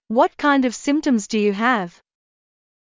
ﾜｯ ｶｲﾝﾄﾞ ｵﾌﾞ ｼﾝﾌﾟﾄﾑｽﾞ ﾄﾞｩ ﾕｳ ﾊﾌﾞ